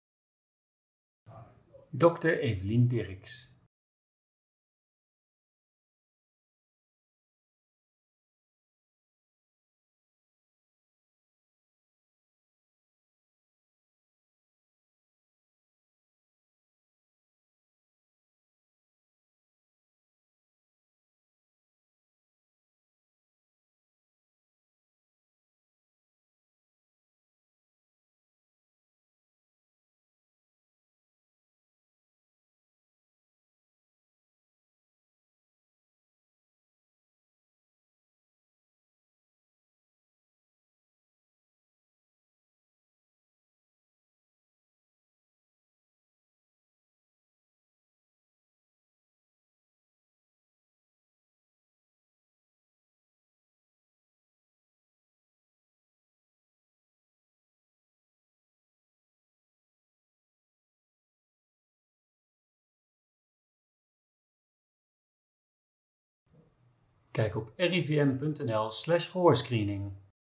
De onderzoeker